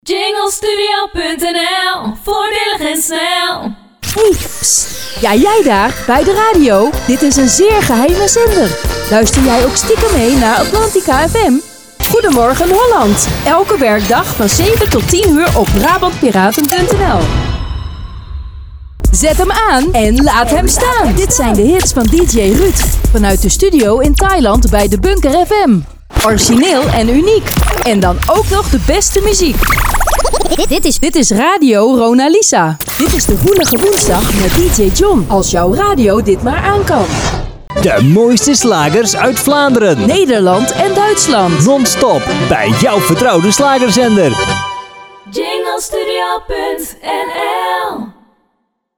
Voorbeeld Vrouwenstem
Demo-vrouwenstem-2025.mp3